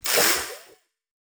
Mech RTS Button Tap.wav